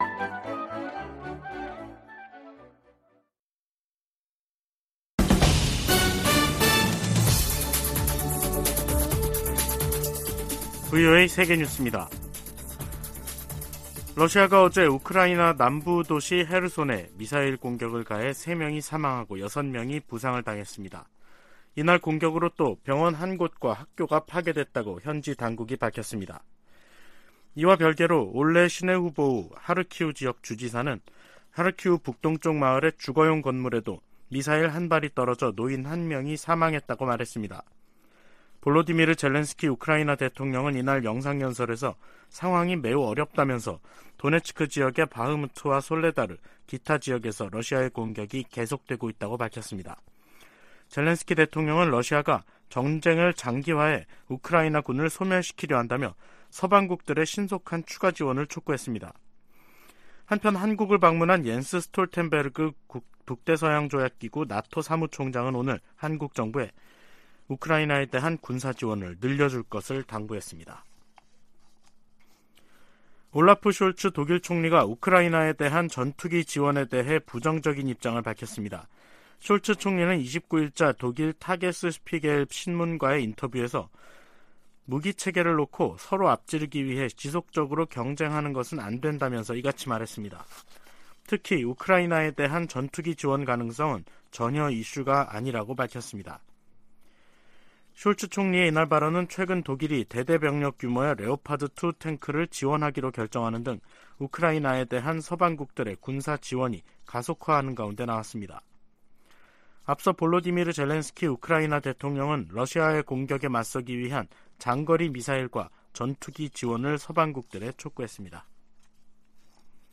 VOA 한국어 간판 뉴스 프로그램 '뉴스 투데이', 2023년 1월 30일 3부 방송입니다. 백악관은 북한이 ‘사이버 업계 전반의 취약한 보안으로 10억 달러 이상을 탈취해 미사일 프로그램에 자금을 조달하는 것이 가능했다’고 지적했습니다. 서울에서 열리는 미한 국방장관 회담에서 확장억제 실행력 강화 방안이 집중 논의될 것이라고 미 군사 전문가들이 전망했습니다.